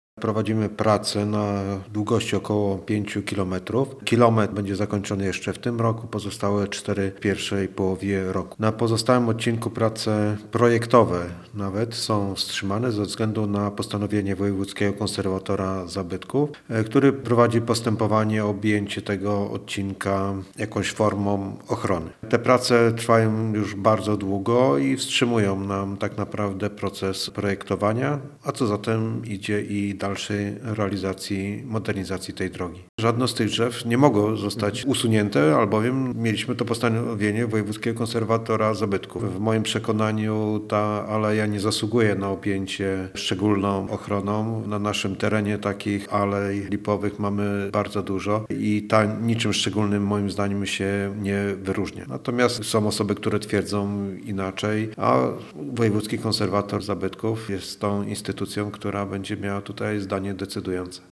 Posłuchaj Leszka Waszkiewicza, starosty bytowskiego: https